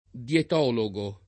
[ d L et 0 lo g o ]